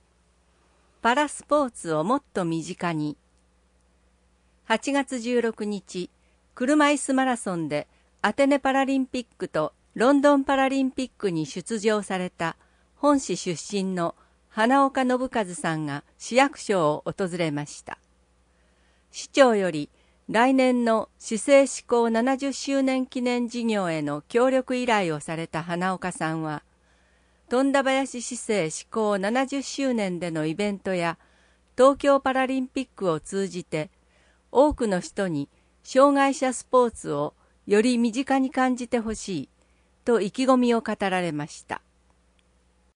本市の依頼により富田林市朗読ボランティアグループ「くさぶえ」が視覚に障がいをお持ちの人などのために製作し、貸し出しているテープから抜粋して放送しています。